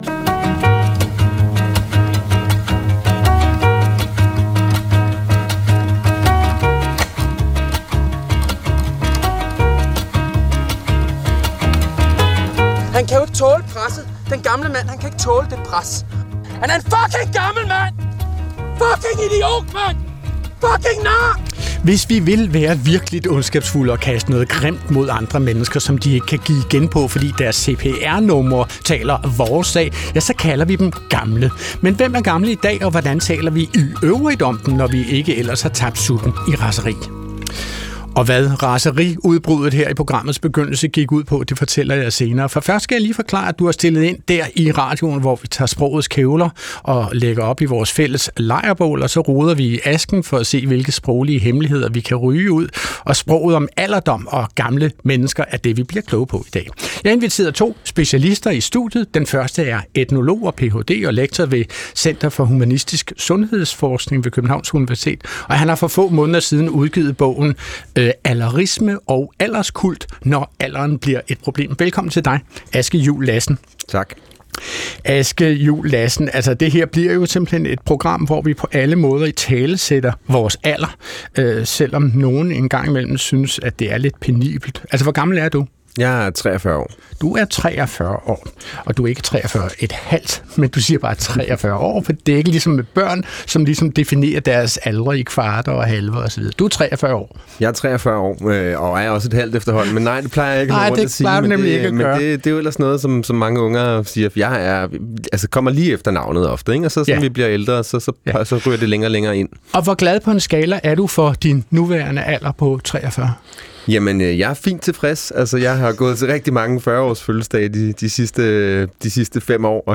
Klog på Sprog er programmet, der interesserer sig for, leger med og endevender det sprog, vi alle sammen taler til daglig. Adrian Hughes er værten, der sammen med et veloplagt panel er helt vild med dansk.